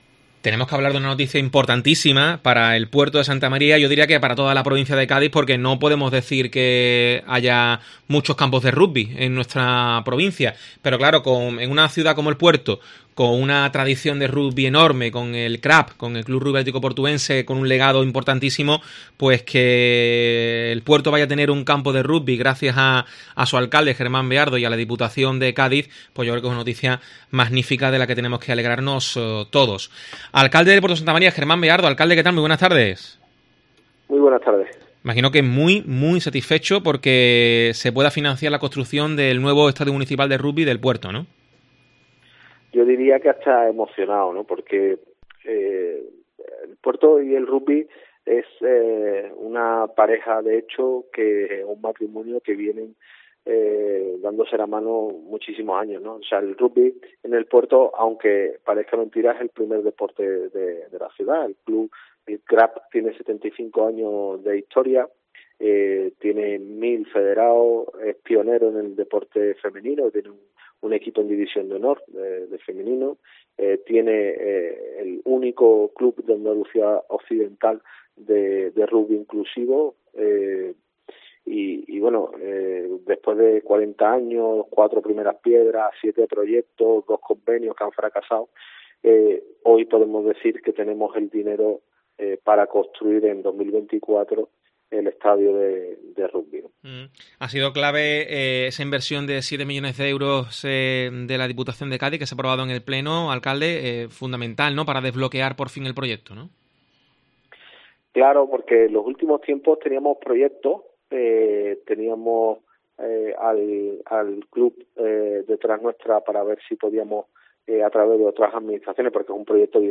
Germán Beardo, alcalde de El Puerto, sobre el nuevo campo de rugby